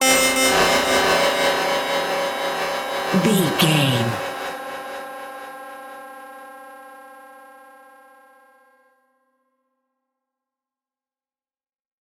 In-crescendo
Thriller
Aeolian/Minor
ominous
dark
suspense
eerie
creepy
synth
keyboards
ambience
pads